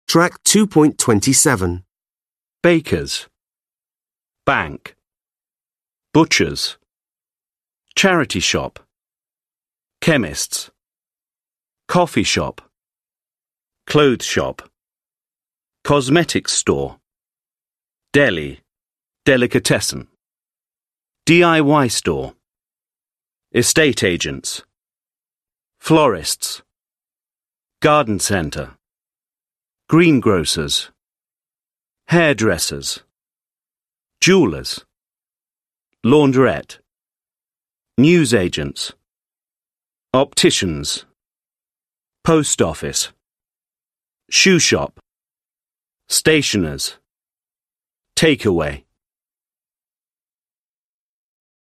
5 (trang 71 Tiếng Anh 10 Friends Global) Listen and repeat all of the shops and services words from exercise 4.